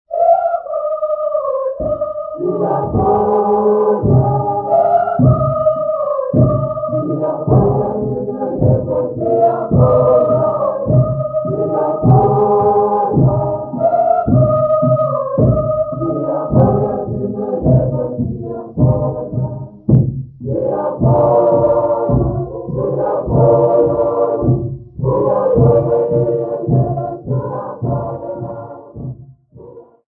Siswati church music workshop participants
Folk music
Sacred music
Field recordings
Siswati church music workshop performance, accompanied by the drum.